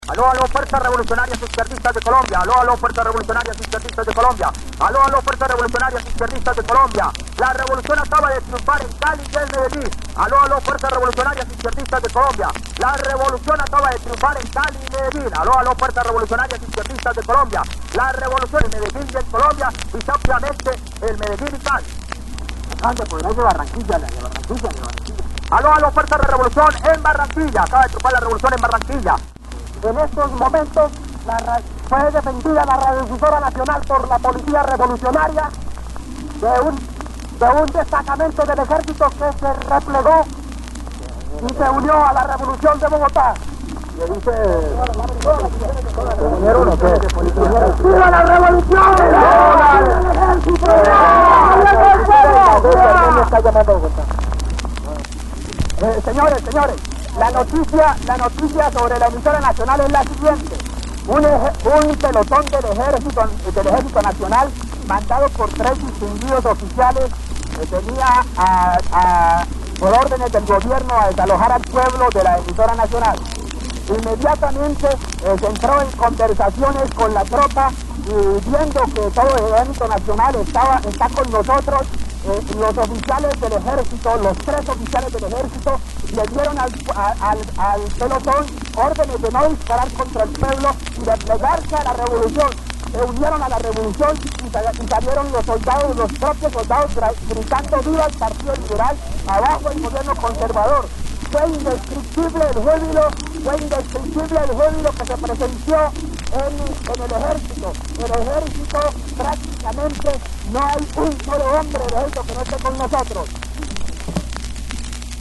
3. El 9 de abril de 1948 a través de los micrófonos de la Radio Nacional de Colombia (Radiodifusora Nacional de Colombia en ese entonces) Jorge Gaitán Durán anuncia el asesinato del caudillo liberal Jorge Eliécer Gaitán.
Audio 4 - Toma Radiodifusora - 1948.mp3